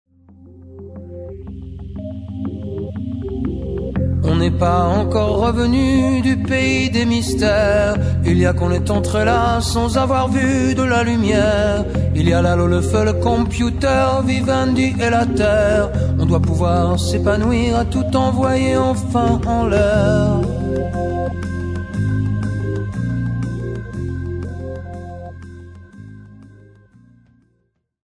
clavier
chœurs